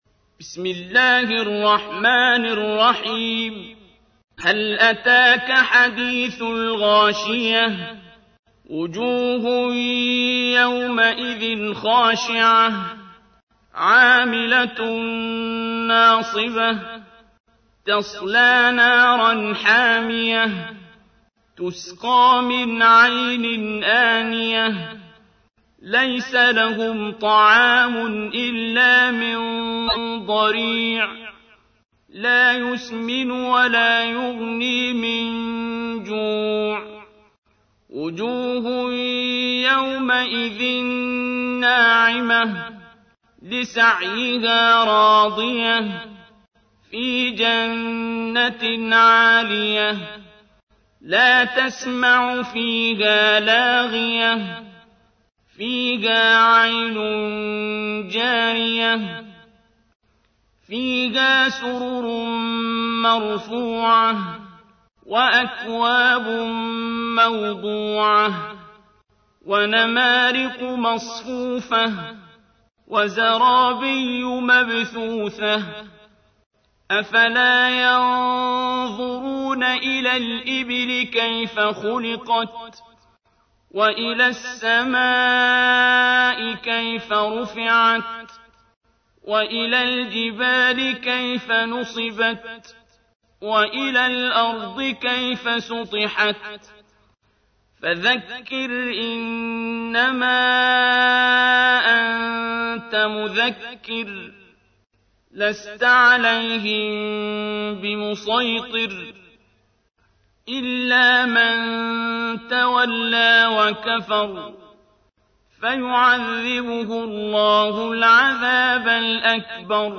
تحميل : 88. سورة الغاشية / القارئ عبد الباسط عبد الصمد / القرآن الكريم / موقع يا حسين